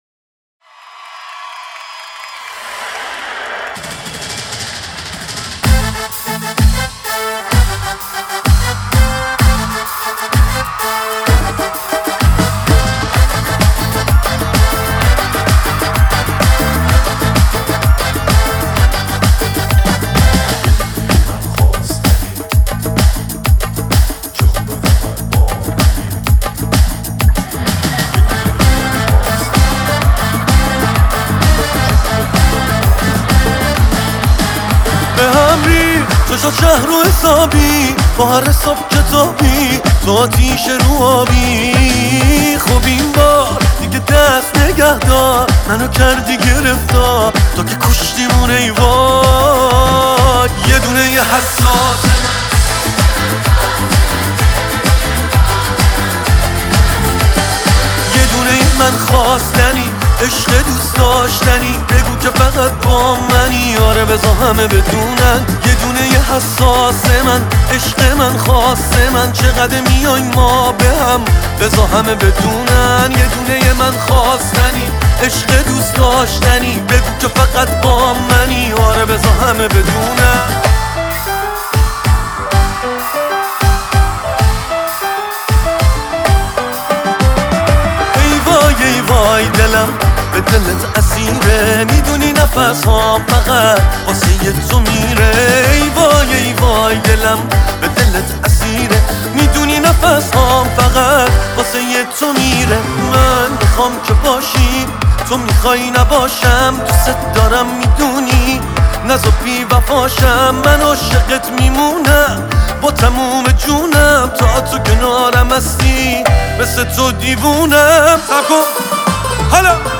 “Live In Concert”